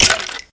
minecraft / sounds / mob / skeleton / hurt3.ogg